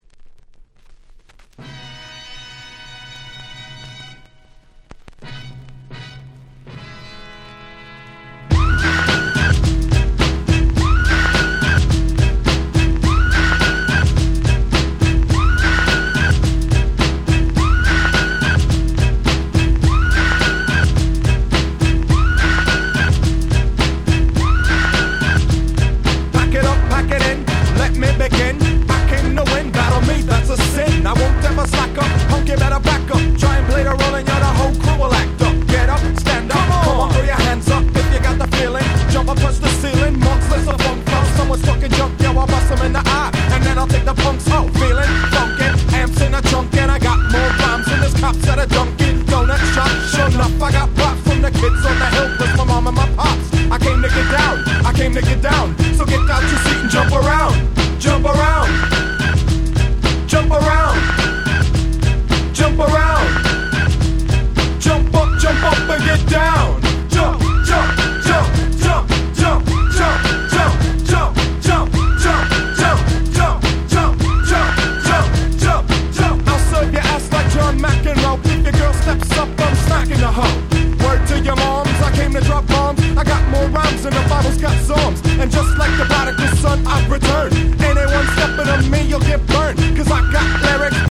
92' Hip Hop Super Classics !!
90's Boom Bap ブーンバップ キャッチー系